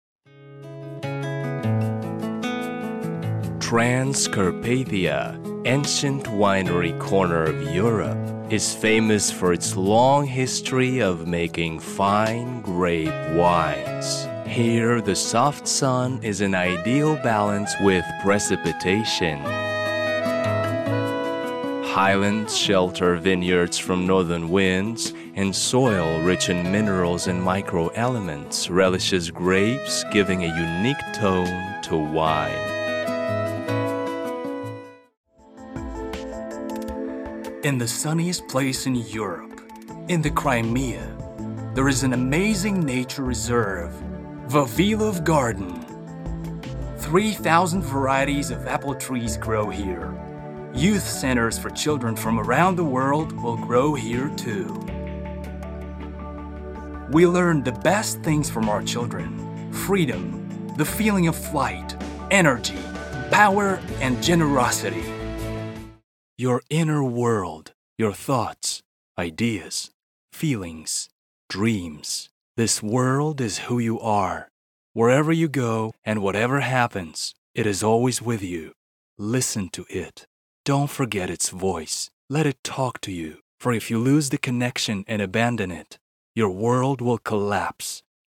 Мужской
Профессиональный англоязычный актер, певец, диктор и мастер боевых искусств.
Баритон